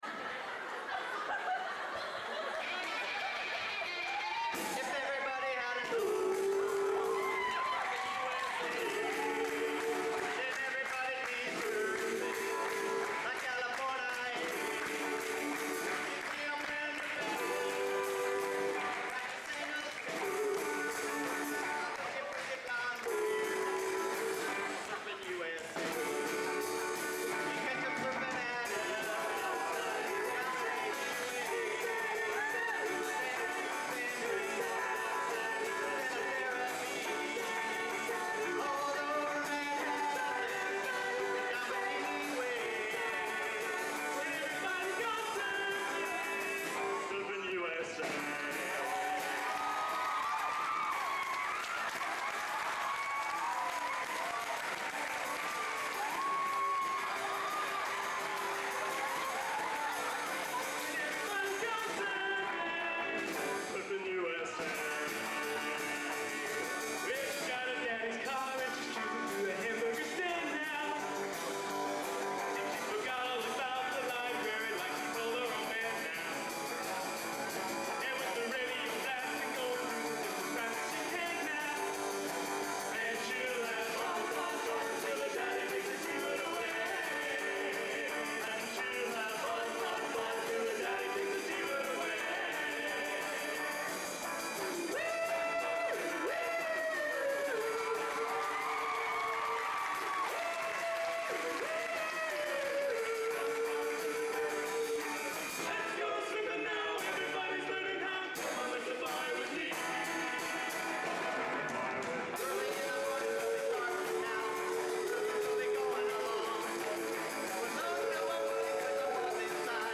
Location: Purdue Memorial Union, West Lafayette, Indiana
Genre: Popular / Standards | Type: